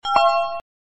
ring.mp3